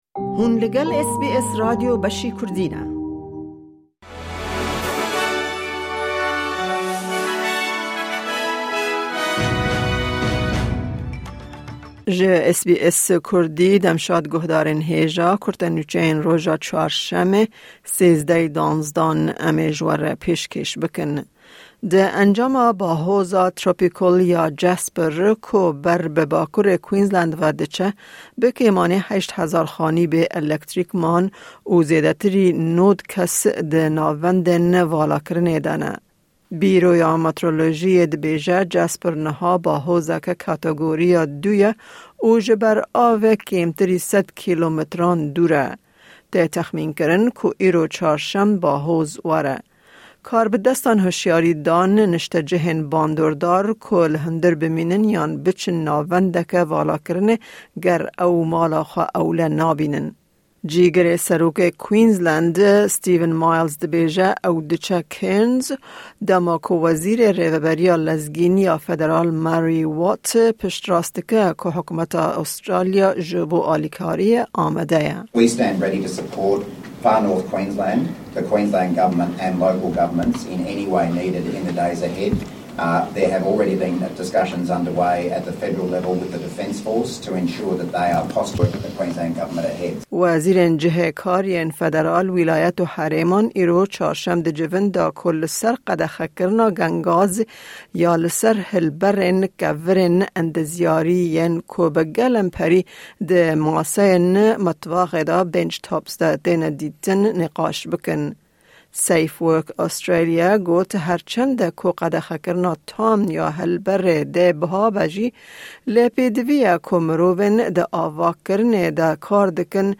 Ew nûçeyana û nûçeyên din di buletenê de hene.